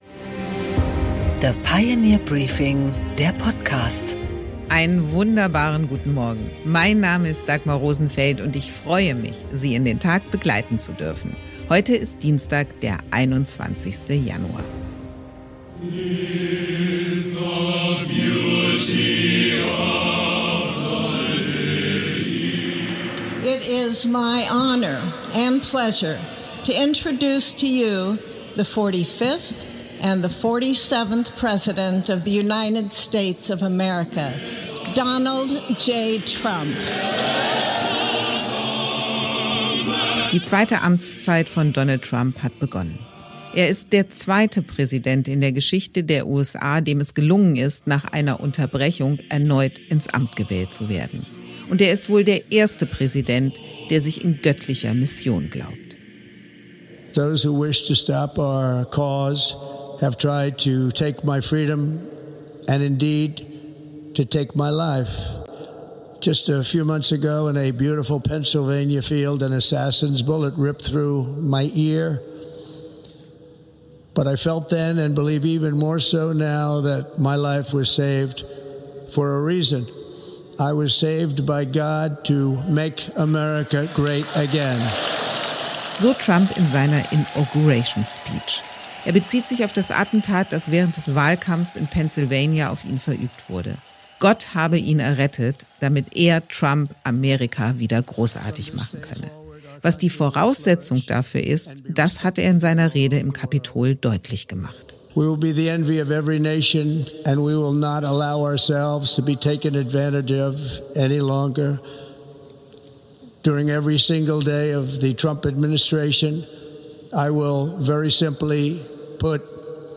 Interview
US-Präsident Donald Trump - die Rede.